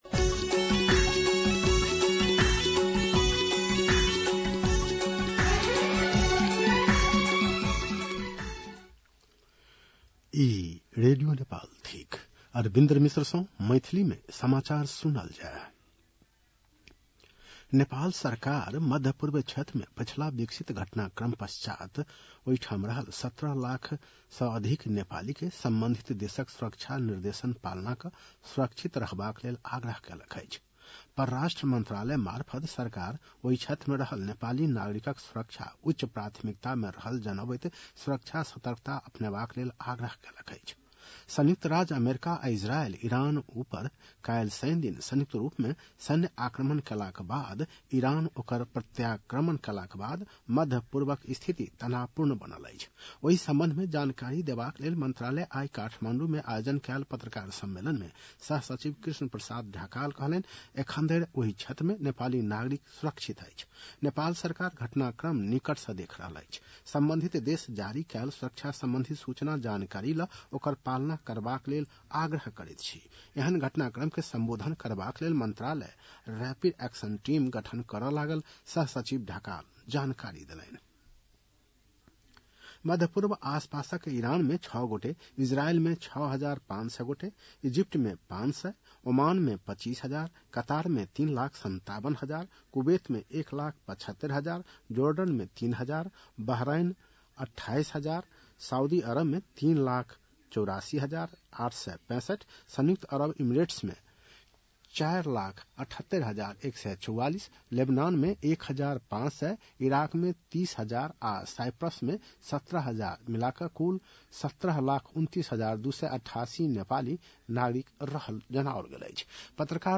An online outlet of Nepal's national radio broadcaster
मैथिली भाषामा समाचार : १७ फागुन , २०८२